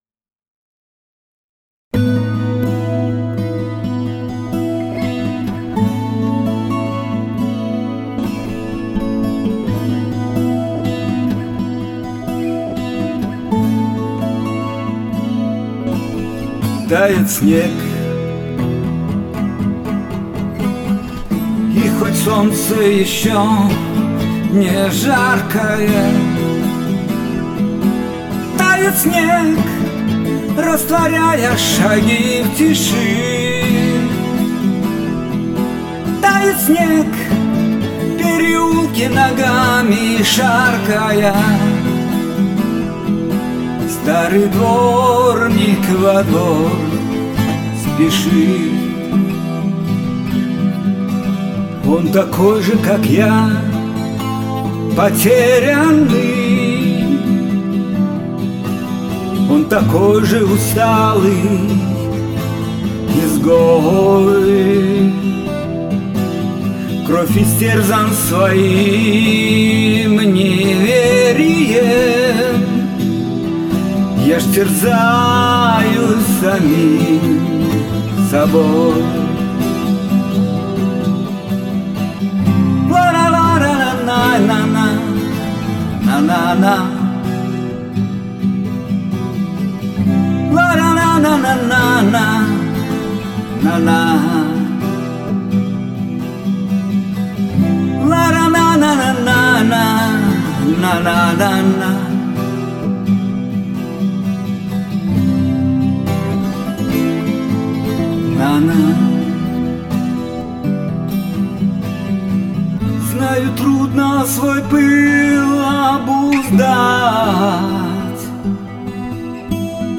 гитара